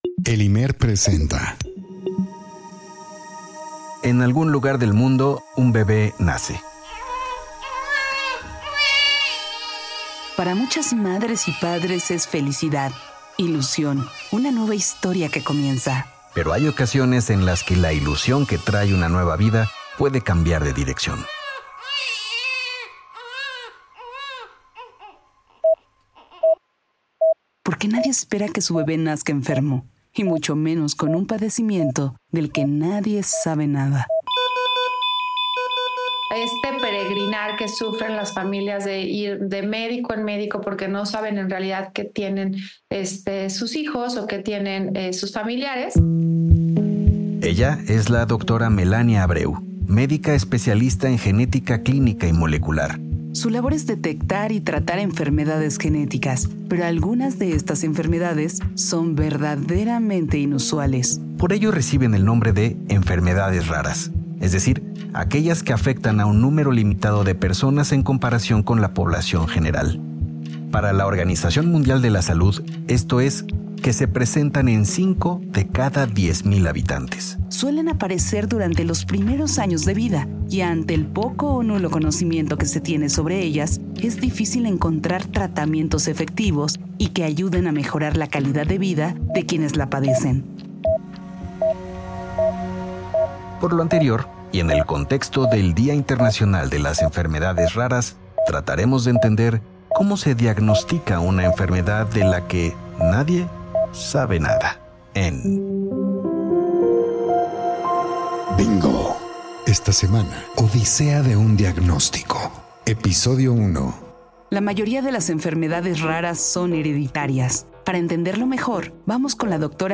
ENTREVISTAS: